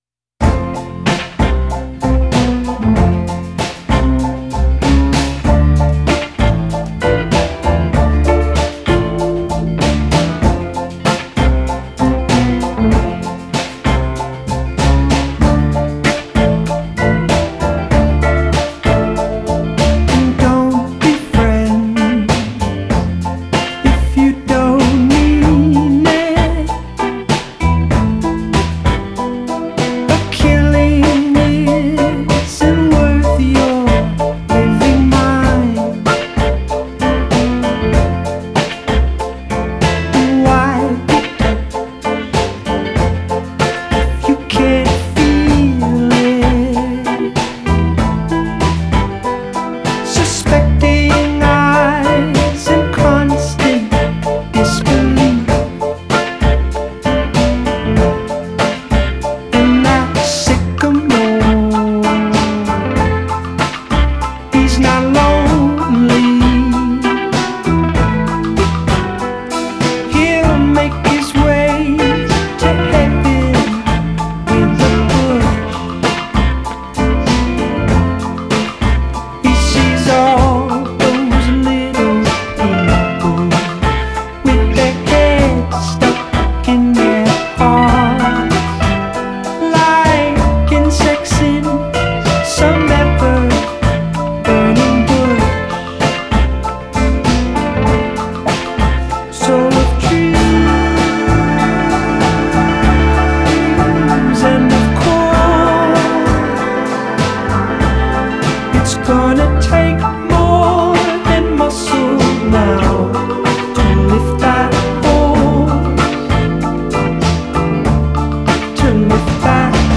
with a melody line that is so catchy it is beguiling